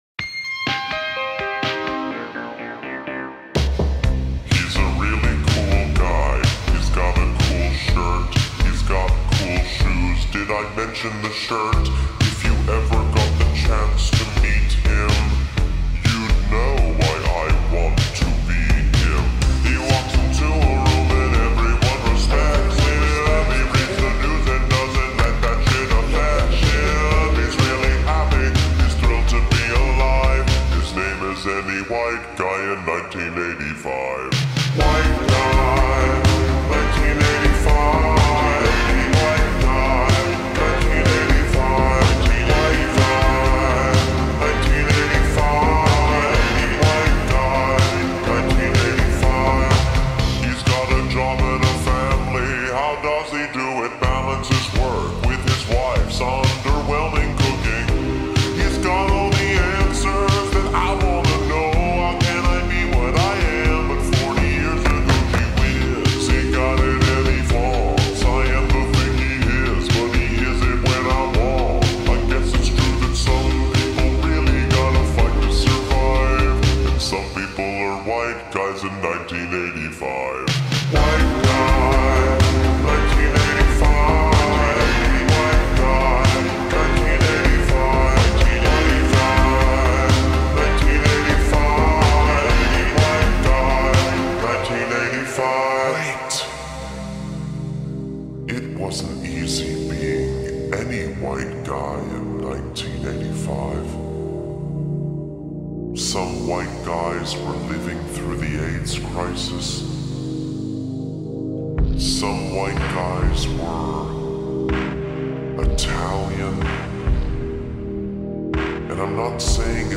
با ریتمی کند شده مناسب ادیت با وایبی خاص و دارک